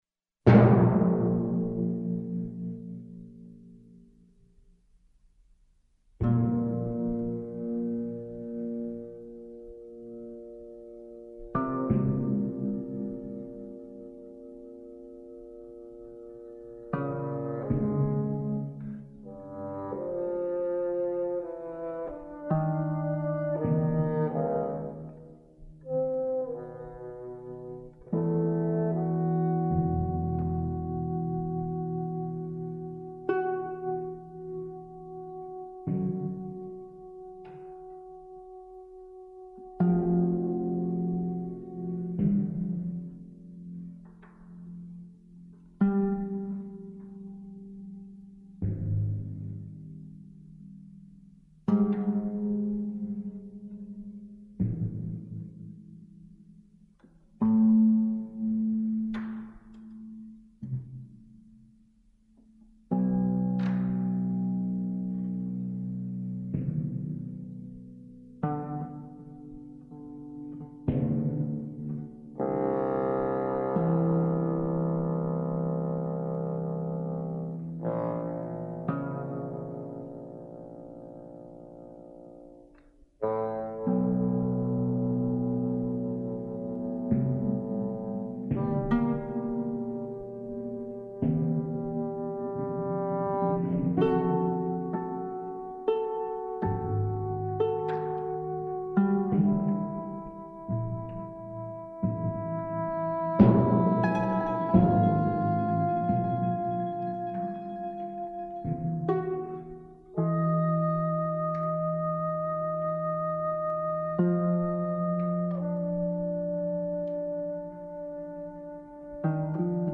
trio
for bassoon, timpani and harp